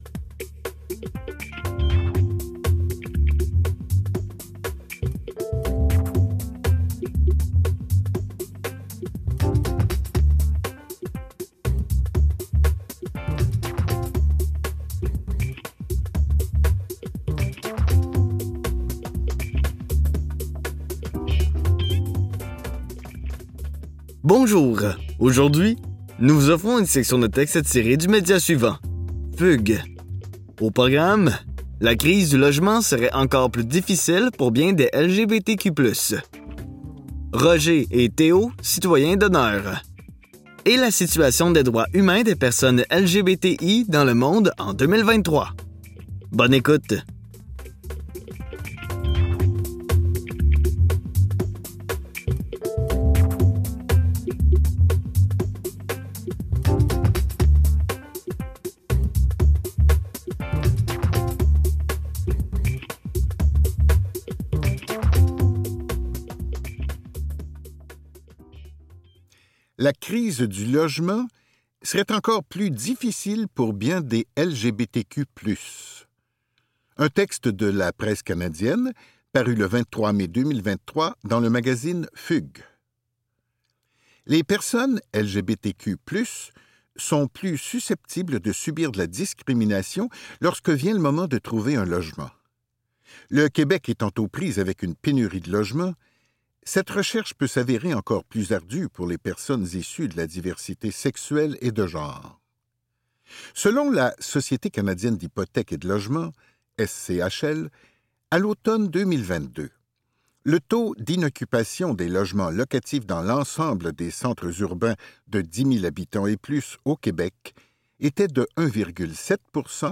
Dans cet épisode de On lit pour vous, nous vous offrons une sélection de textes tirés du média suivant : Fugues.